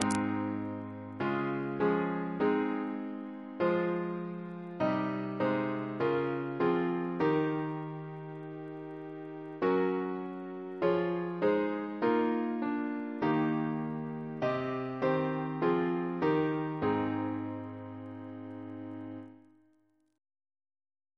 CCP: Chant sampler
Double chant in A♭ Composer: George Thalben-Ball (1896-1987), Organist of the Temple Church Note: for Psalm 24 Reference psalters: ACP: 51; RSCM: 132